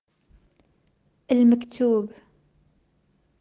Por cierto, se pronuncia parecido a [mak-tub].
mak-tub.ogg